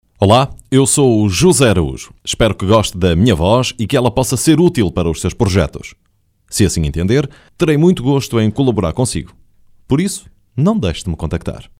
portugiesischer Sprecher.
Kein Dialekt
Portuguese voice over talent.